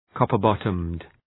Shkrimi fonetik {,kɒpər’bɒtəmd}